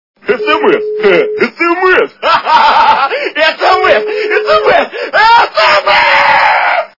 При прослушивании Мужской Голос - Sms, sms, sms (переходящий в крик) качество понижено и присутствуют гудки.
Звук Мужской Голос - Sms, sms, sms (переходящий в крик)